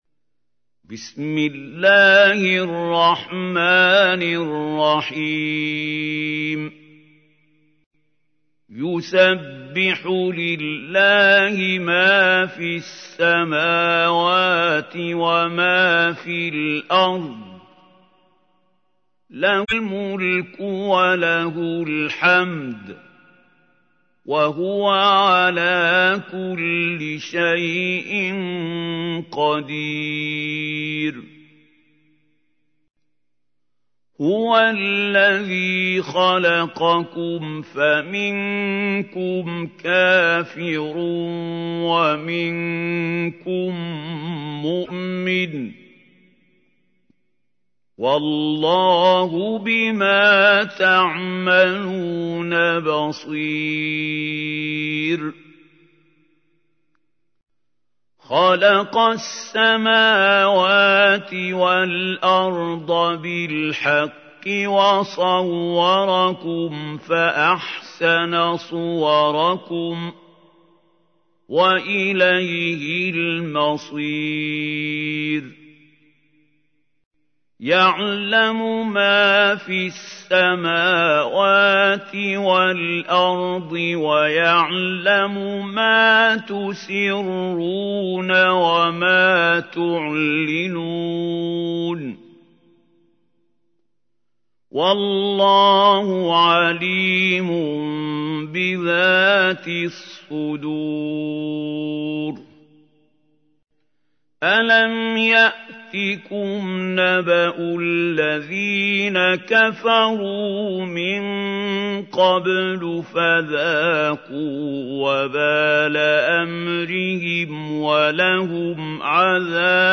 تحميل : 64. سورة التغابن / القارئ محمود خليل الحصري / القرآن الكريم / موقع يا حسين